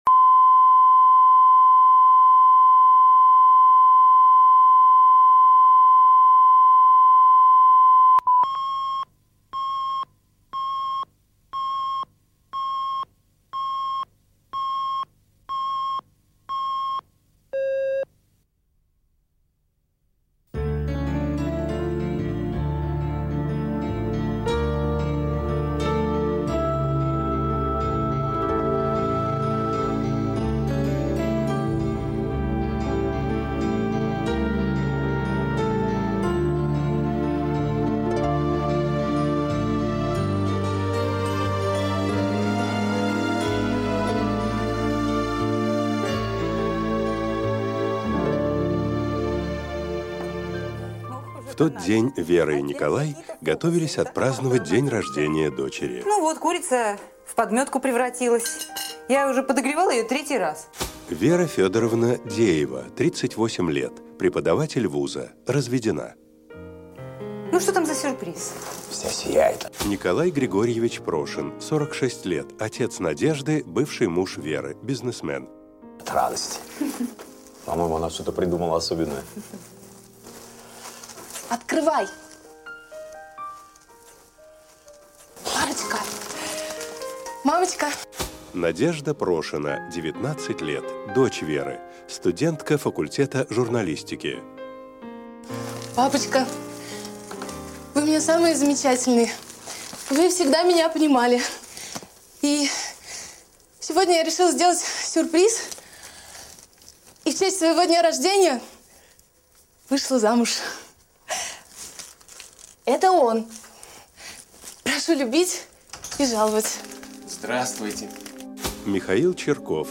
Аудиокнига Зима в сердце | Библиотека аудиокниг